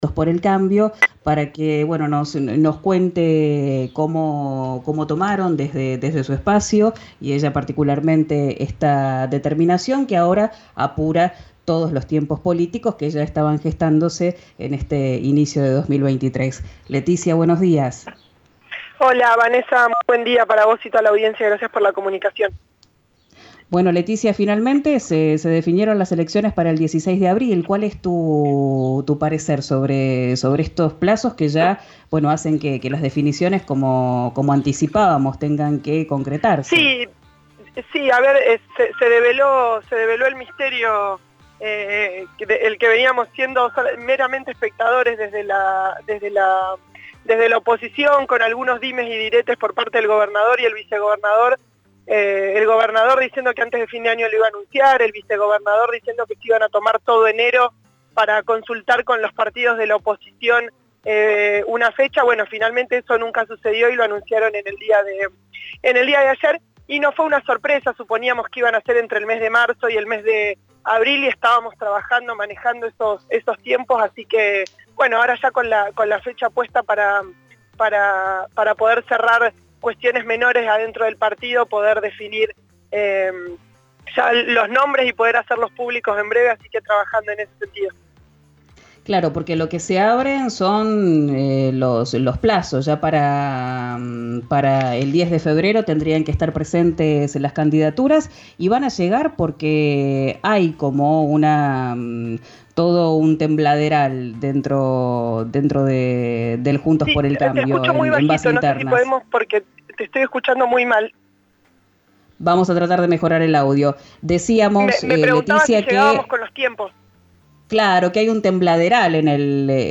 Escuchá a la diputada de Neuquén por el PRO, Leticia Esteves, en “Quién dijo verano”, por RÍO NEGRO RADIO